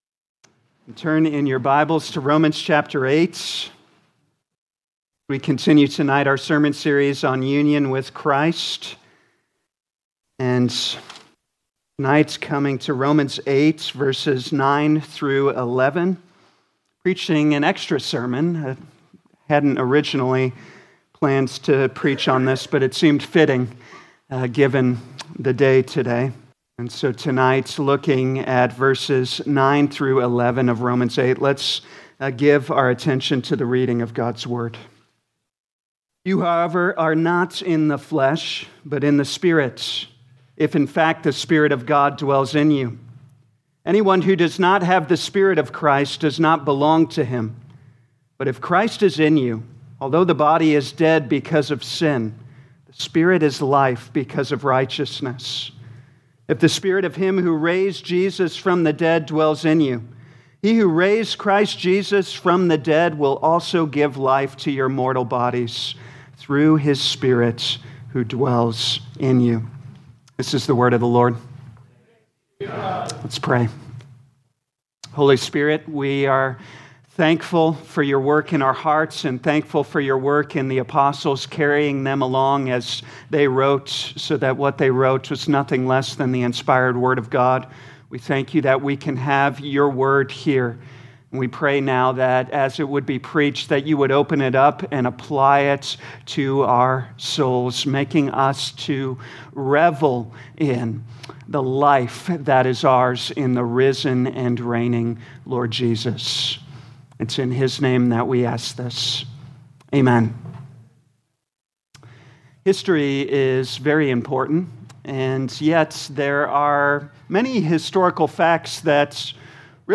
2026 Romans Evening Service Download: Audio Notes Bulletin All sermons are copyright by this church or the speaker indicated.